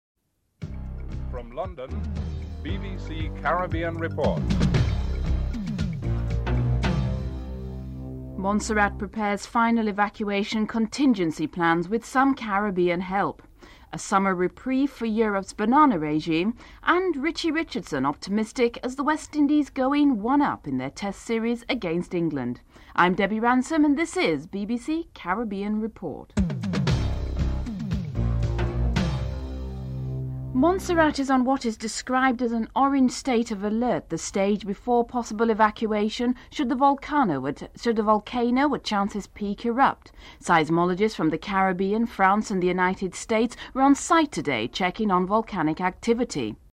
England's captain Mike Atherton comments on the Old Trafford pitch and West Indies captain Richie Richardson is optimistic about another victory.